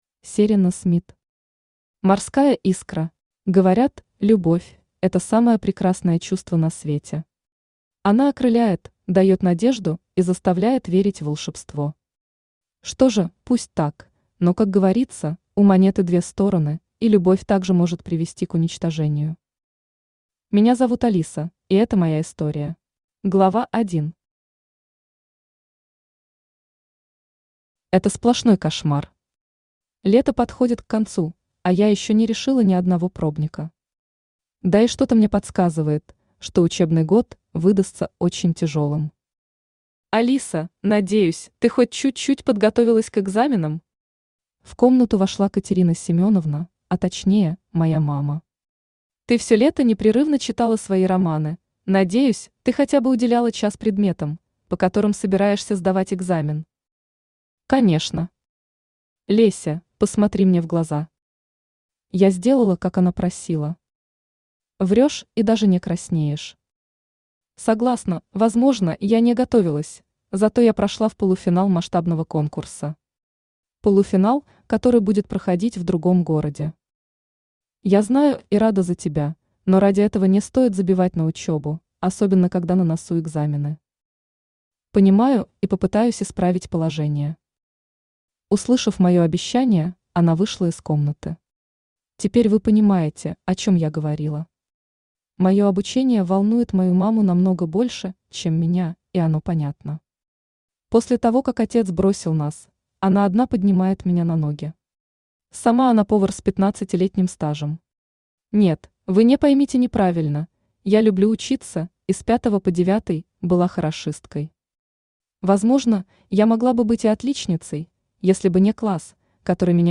Аудиокнига Морская искра | Библиотека аудиокниг
Aудиокнига Морская искра Автор Серено Смит Читает аудиокнигу Авточтец ЛитРес.